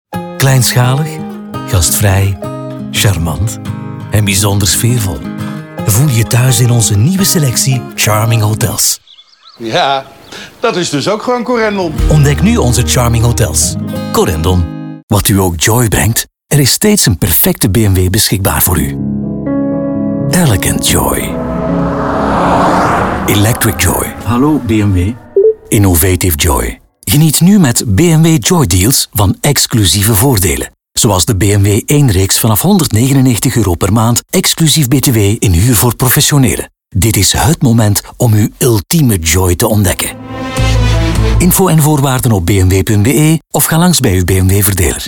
Flemish, Male, Studio, 40s-50s.